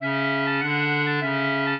clarinet
minuet5-2.wav